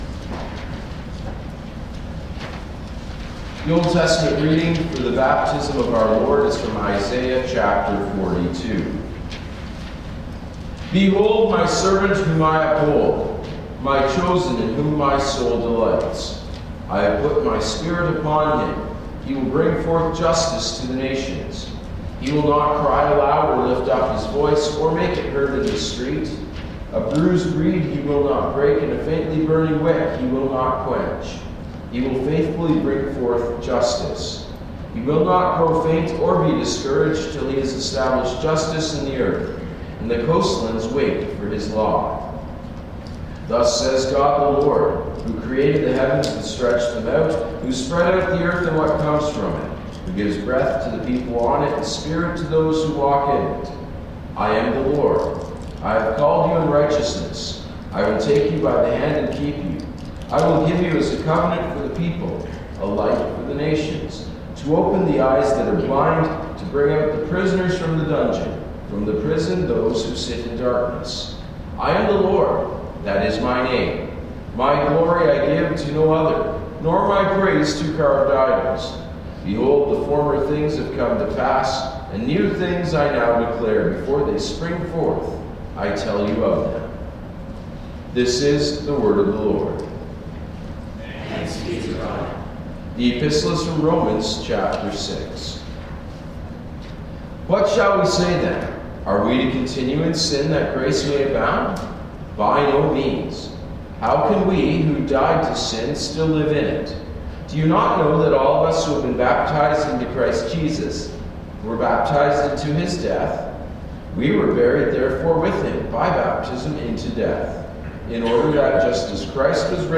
Readings and Sermon – January 11, 2026